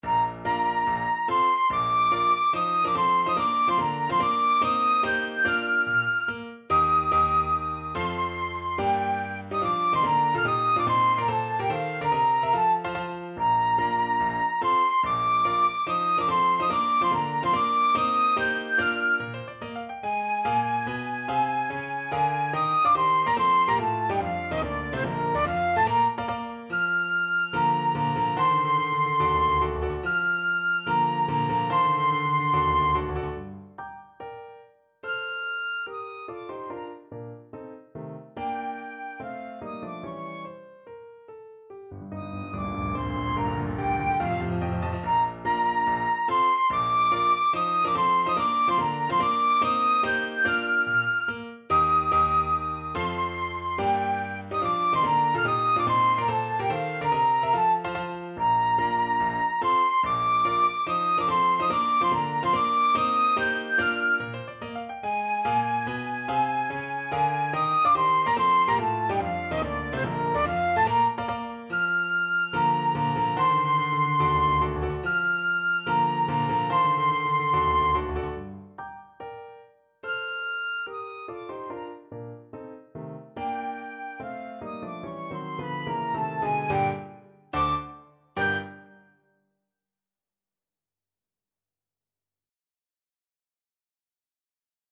Classical Berlioz, Hector March to the Scaffold from Symphonie Fantastique (Excerpt) Flute version
4/4 (View more 4/4 Music)
Bb major (Sounding Pitch) (View more Bb major Music for Flute )
Allegro non troppo (=72) (View more music marked Allegro)
Classical (View more Classical Flute Music)